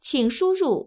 ivr-please_enter_the.wav